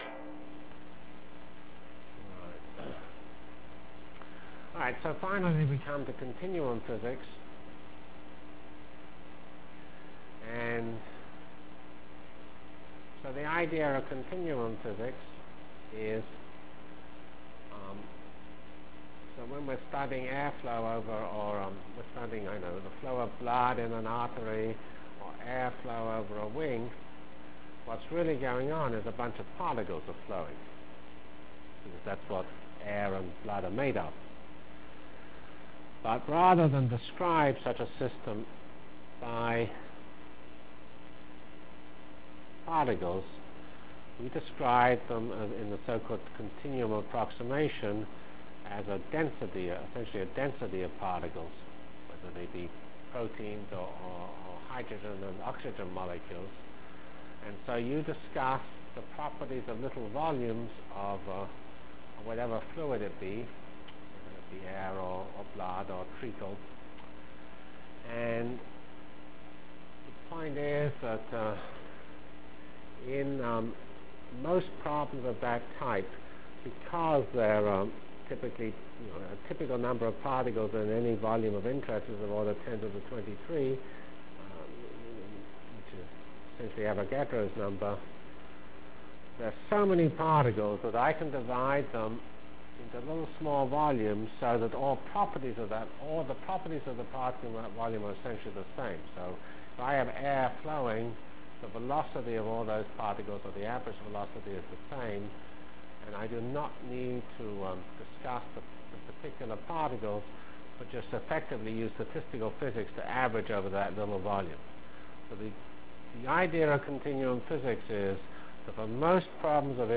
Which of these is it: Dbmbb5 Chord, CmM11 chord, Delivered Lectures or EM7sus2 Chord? Delivered Lectures